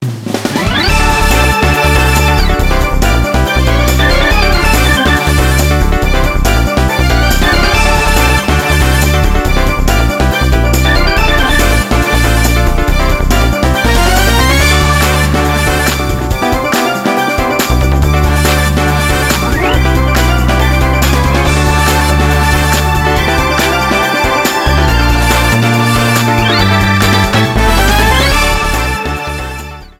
Ripped from the ISO
Faded in the end